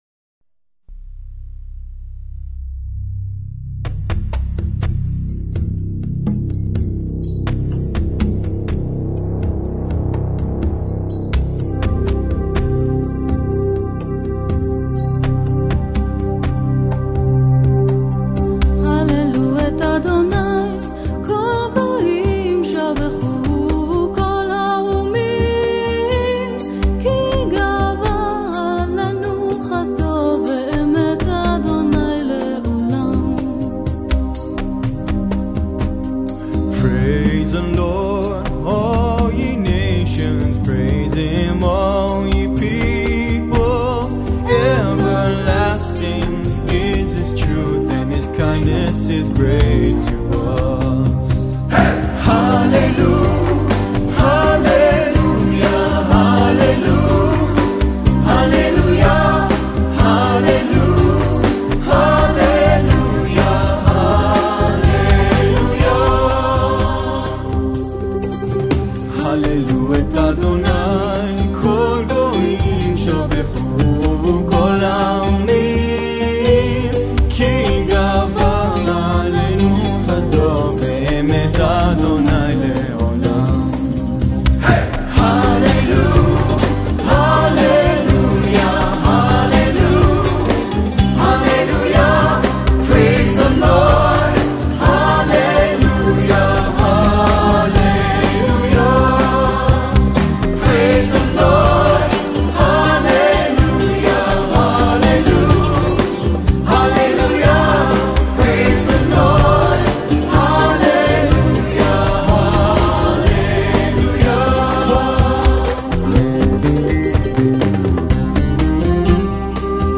赞美雅伟Praise-the-Lord-慢板.mp3